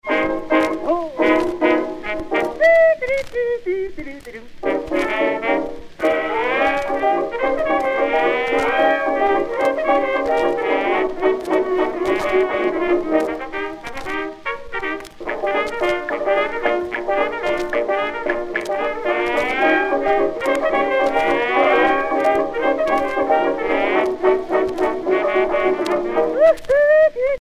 danse : charleston
Pièce musicale éditée